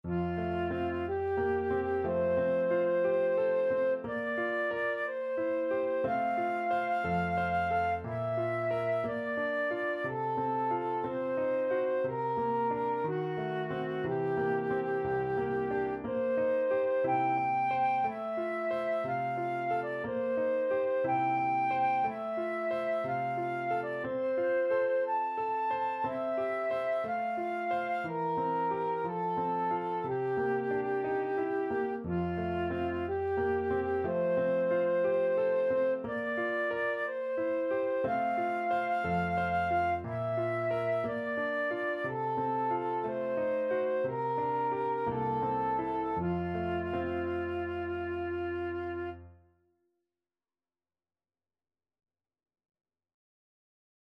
Classical Granados, Enrique Dedicatoria (from Cuentos de la Juventud, Op.1) Flute version
Flute
F major (Sounding Pitch) (View more F major Music for Flute )
2/4 (View more 2/4 Music)
~ = 60 Andantino (View more music marked Andantino)
Classical (View more Classical Flute Music)